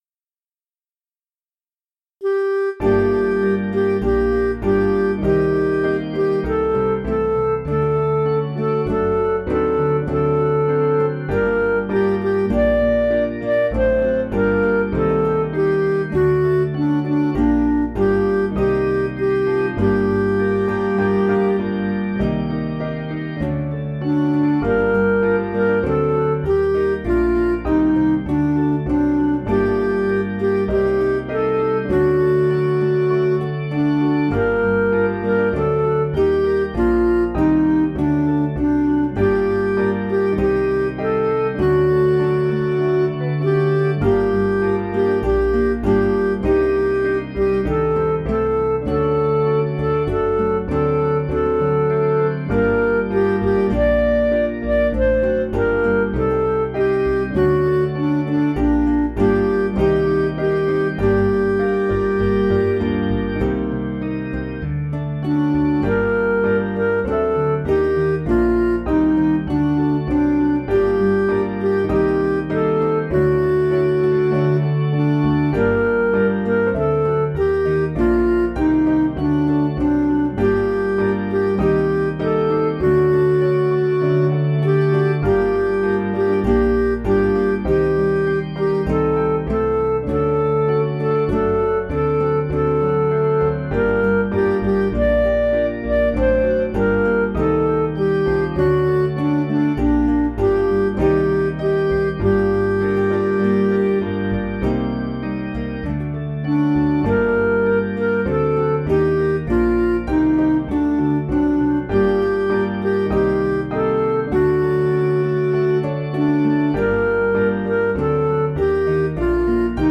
Piano & Instrumental
(CM)   4/Gm
Midi